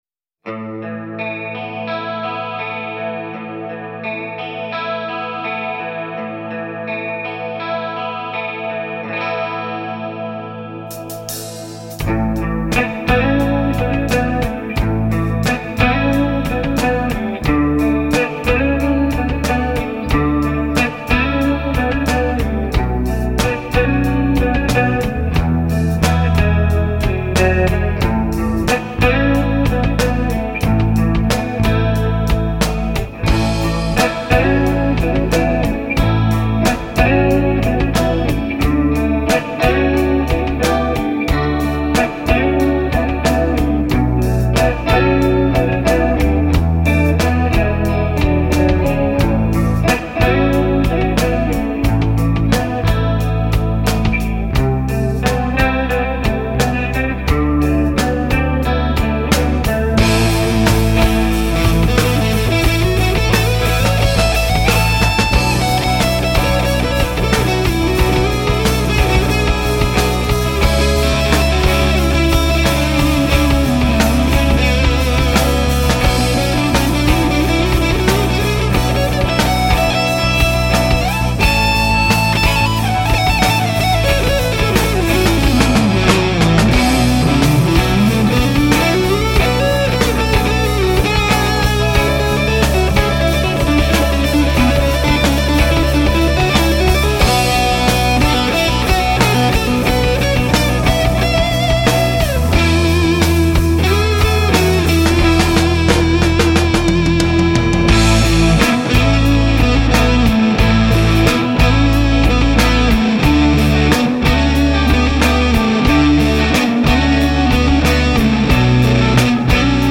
Žánr: Rock
instrumentální